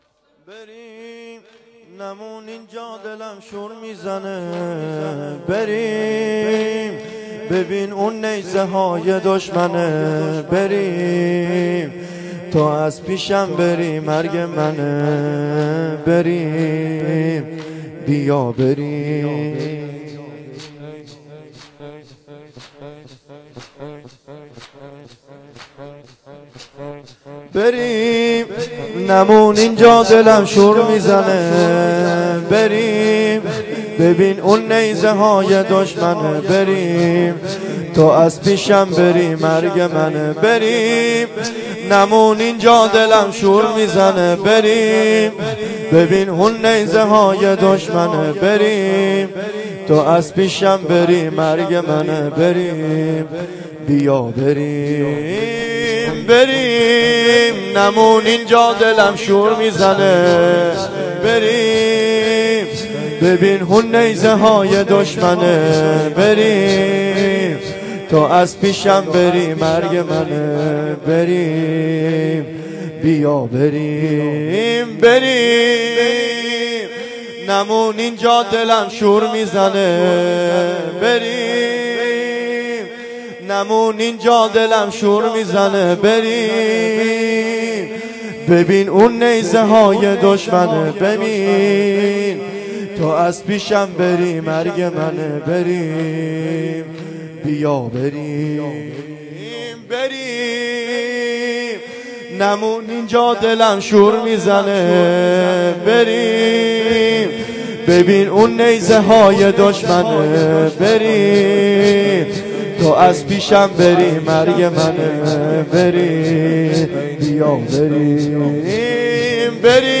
دوضرب